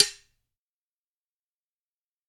sword_C
field-recording impact metal metallic ping sword sound effect free sound royalty free Sound Effects